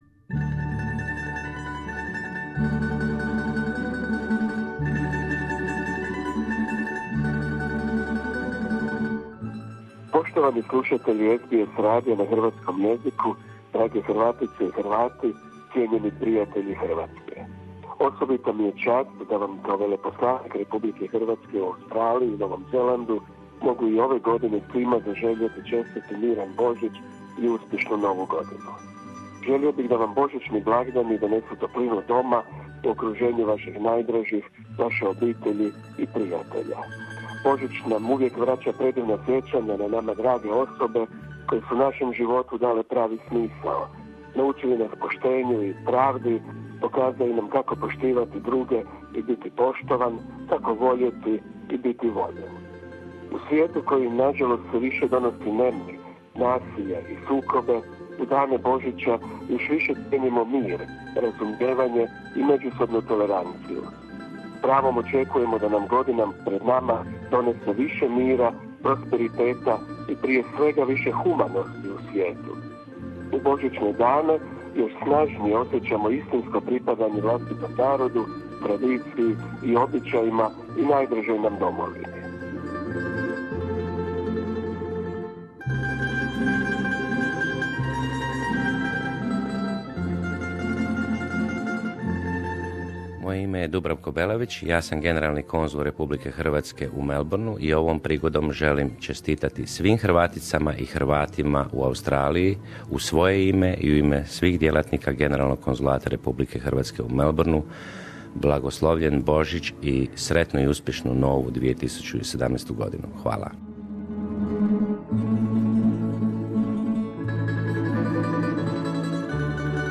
Čestitke za Božić i novu godinu upućuju predstavnici Republike Hrvatske u Australiji, te čelnih ljudi organizacija i udruga hrvatske zajednice